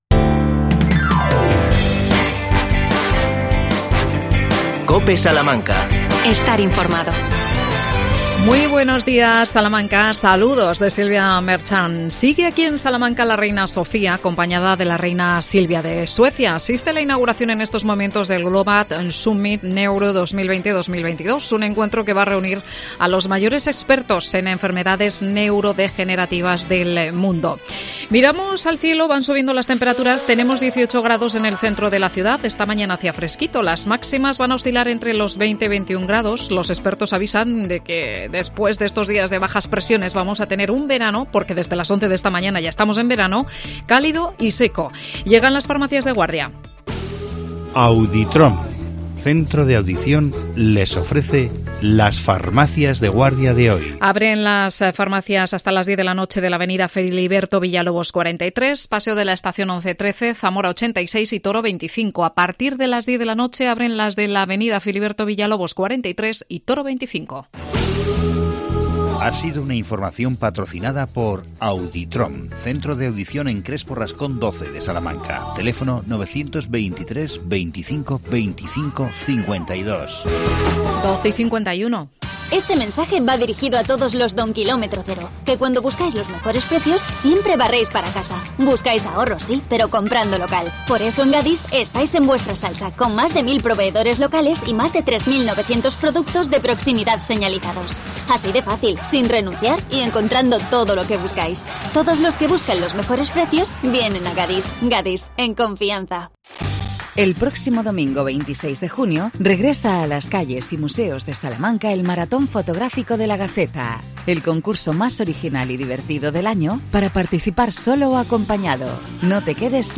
AUDIO: Entrevista a la concejala de Participación Ciudadana Almudena Parres. El tema: los consejos zonales.